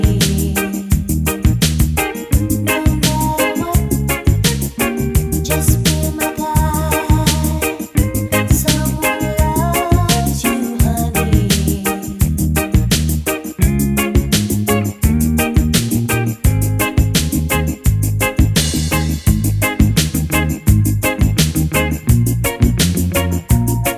For Solo Female Reggae 3:59 Buy £1.50